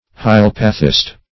Hylopathist \Hy*lop"a*thist\, n.
hylopathist.mp3